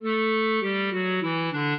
clarinet
minuet6-3.wav